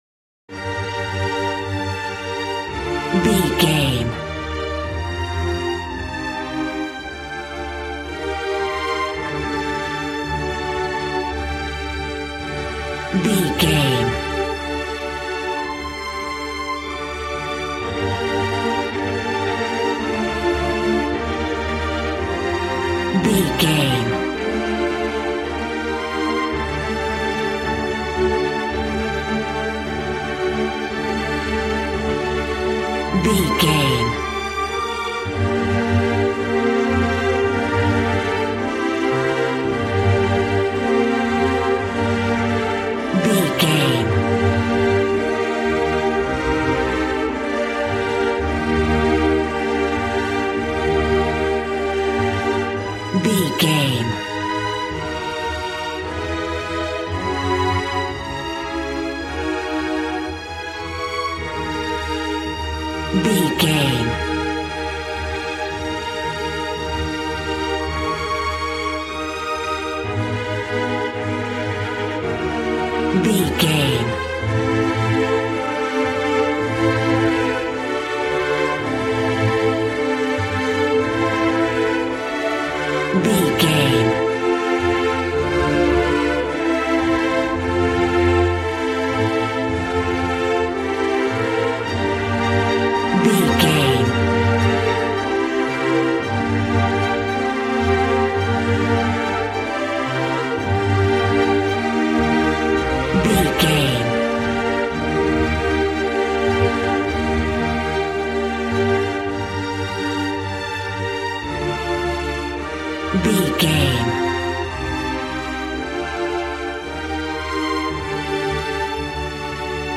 Regal and romantic, a classy piece of classical music.
Aeolian/Minor
regal
cello
violin
strings